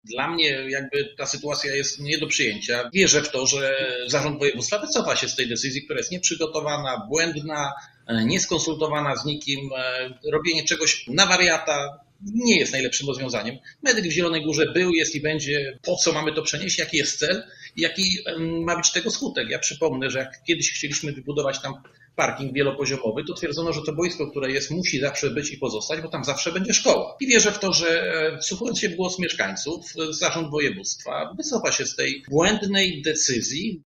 Jak dodawał prezydent Zielonej Góry, nikt z Zarządu Województwa Lubuskiego nie konsultował tej decyzji z miastem: